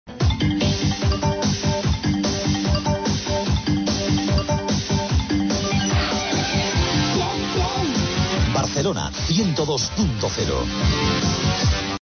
a45ec14b8053d0b8da0ef9f2abe88881ae4ff8a0.mp3 Títol Cadena 100 Barcelona Emissora Cadena 100 Barcelona Cadena Cadena 100 Titularitat Privada estatal Descripció Identificació, amb l'antiga freqüència, als 102 MHz.